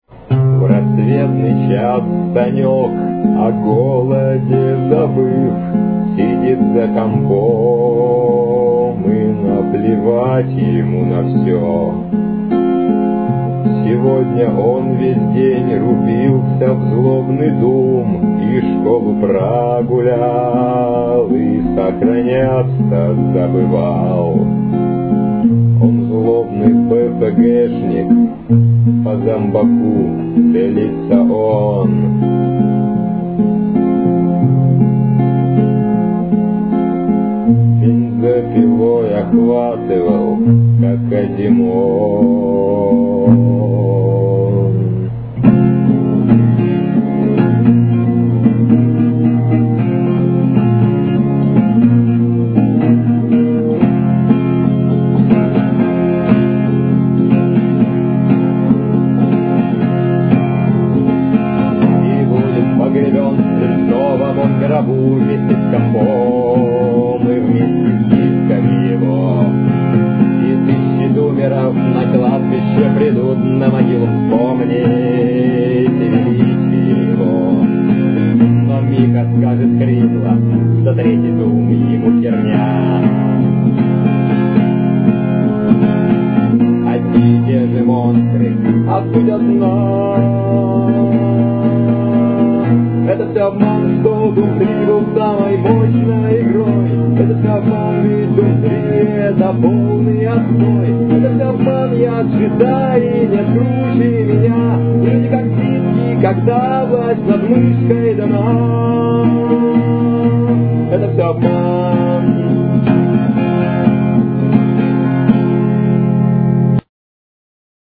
играю на гитаре. так для компании. не профессионально
качество фиговенькое... ибо микрофон такой же...